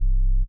Lowende.wav